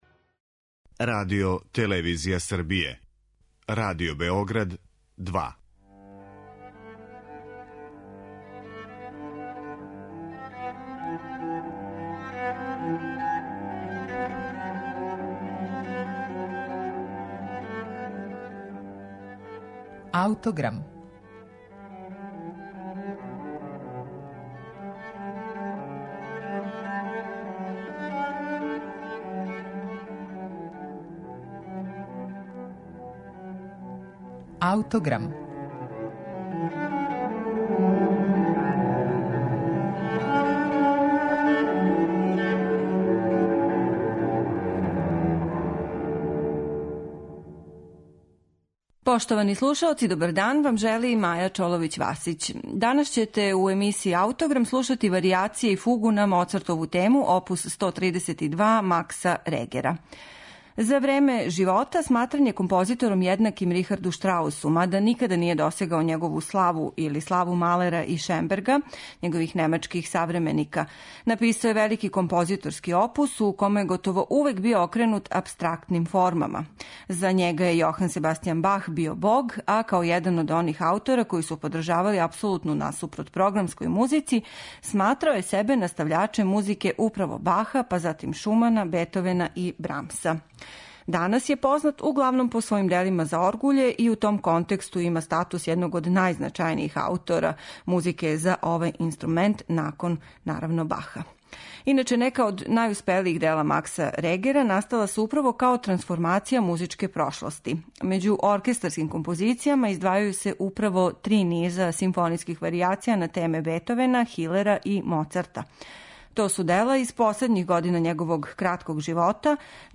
У данашњој емисији слушаћете Симфонијски оркестар Југозападног немачког радија, којим диригује Еса Пека Салонен.